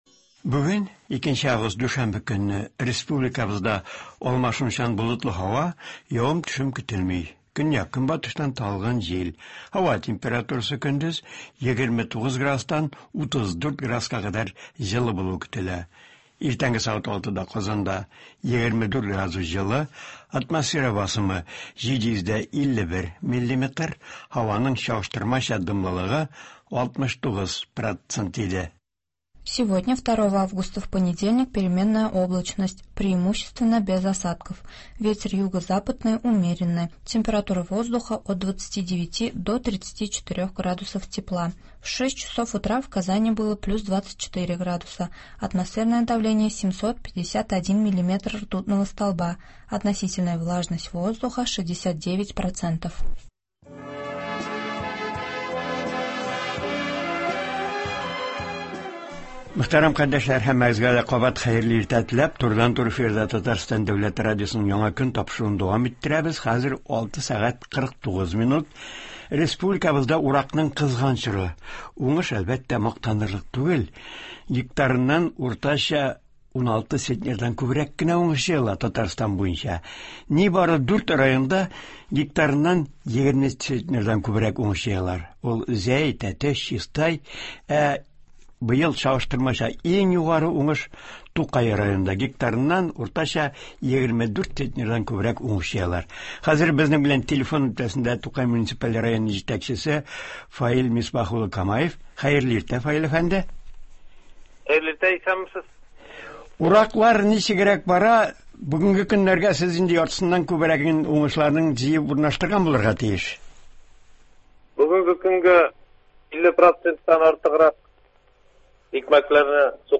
Чагыштырмача иң югары уңыш җыючы Тукай районы хакимияте башлыгы Фаил Камаев телефон элемтәсе аша районда уракның барышы турында сөйли.